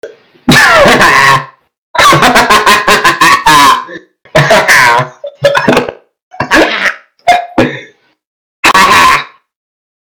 (D)(Pause)Laughing